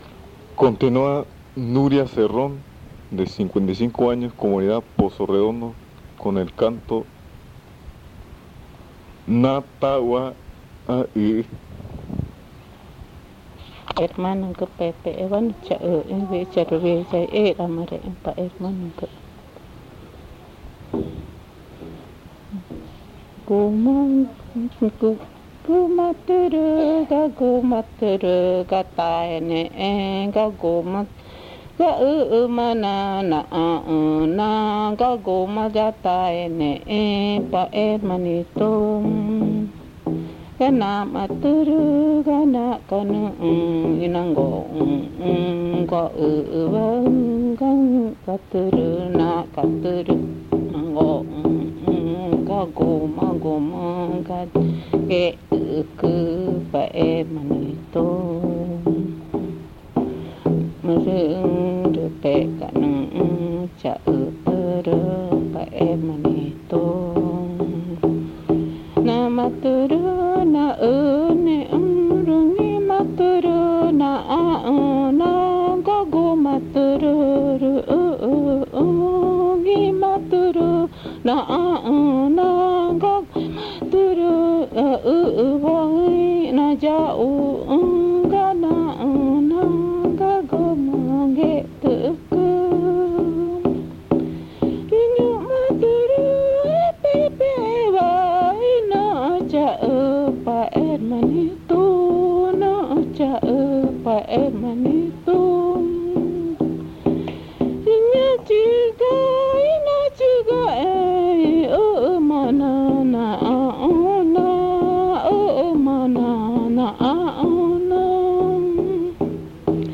Canto de la muchacha que salió de la leña
Pozo Redondo, Amazonas (Colombia)